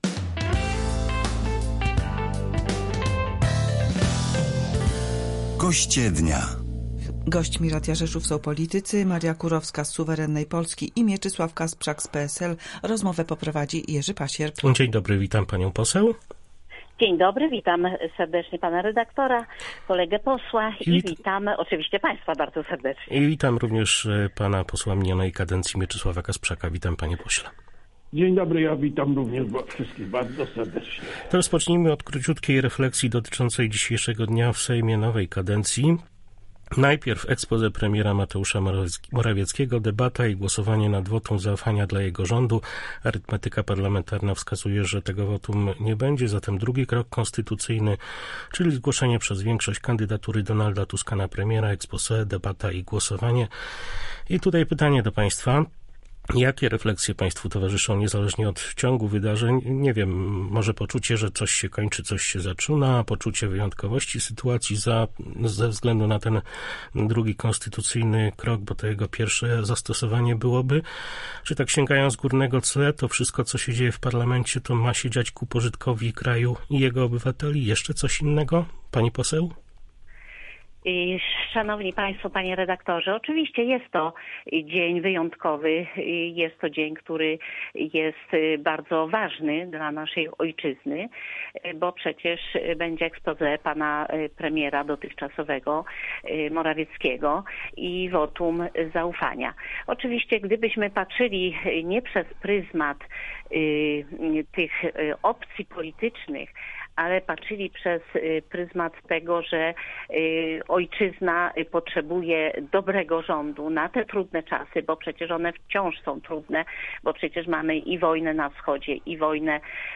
Wybór nowego rządu i podsumowanie dotychczasowych rządów PiS to główne tematy naszej dzisiejszej rozmowy z podkarpackimi politykami.